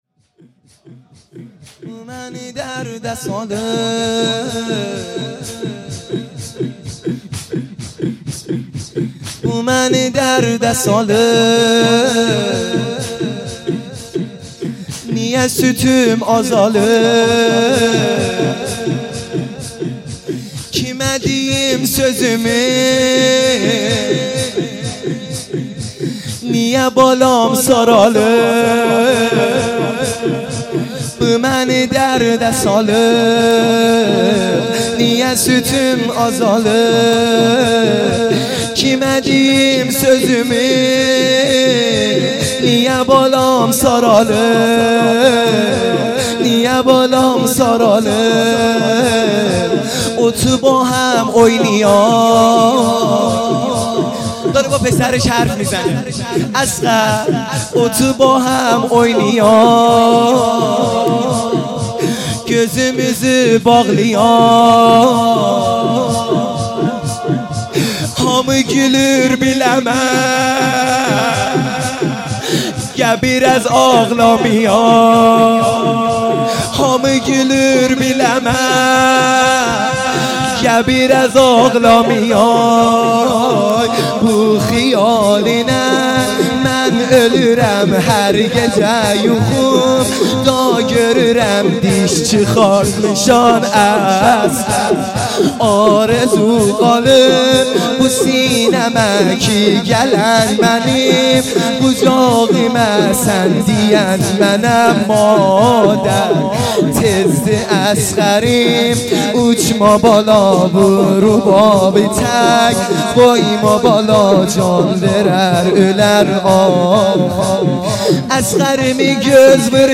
لطمه زنی | بومنی درد سالیب
شب هفتم محرم الحرام ۱۳۹۶